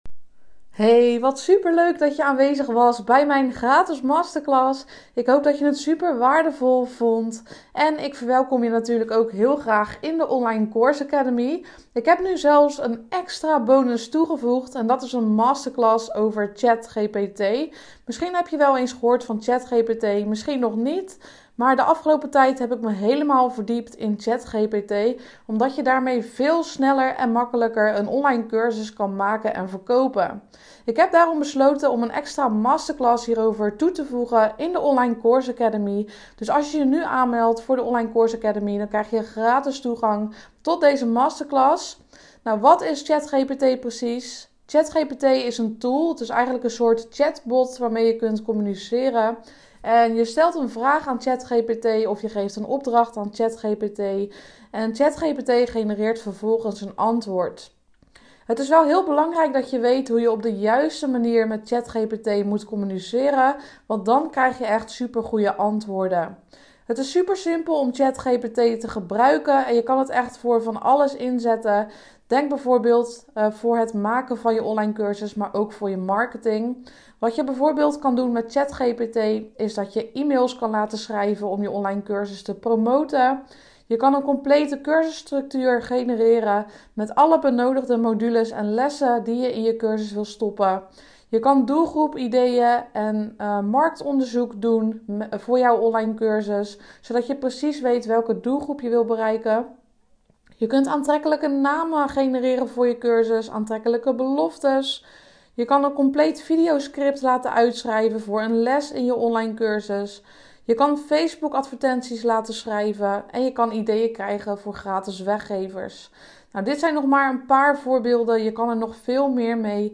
Beluister nu het voice berichtje